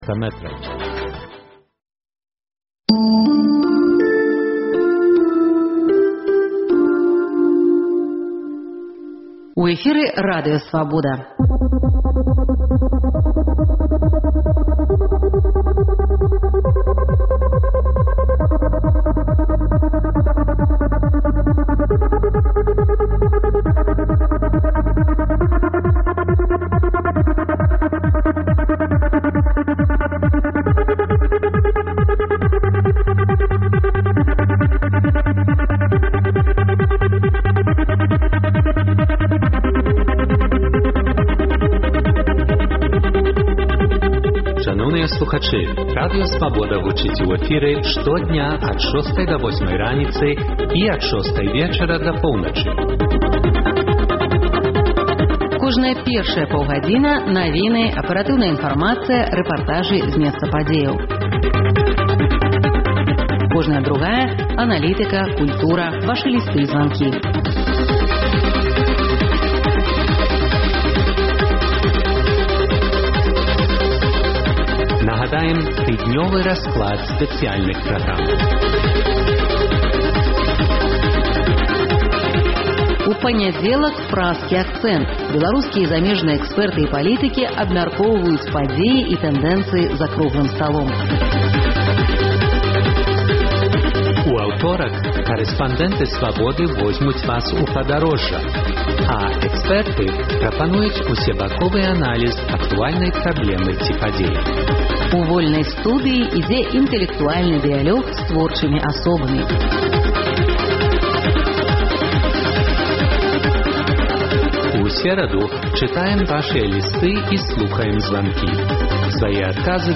Слухайце ад 12:00 да 14:00 жывы эфір "Свабоды"!